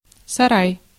Ääntäminen
IPA: /səˈraj/